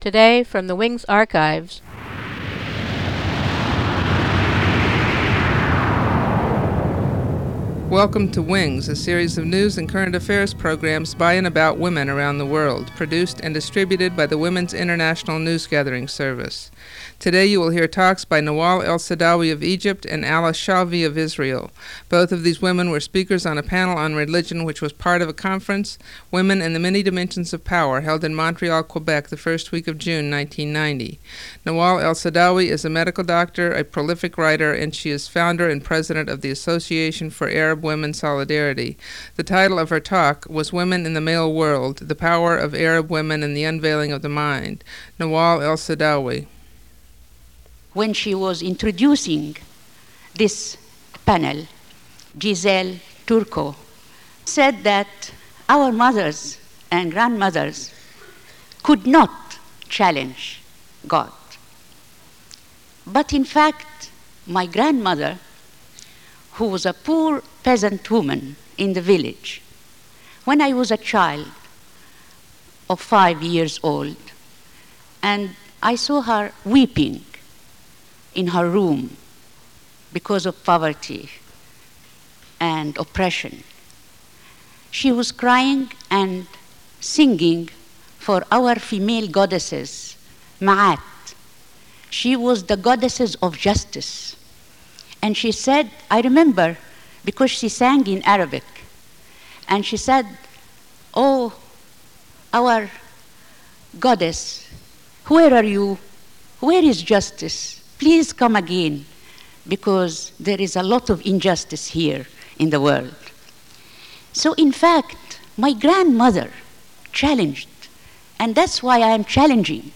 Egyptian and Israeli Feminists, speaking in 1990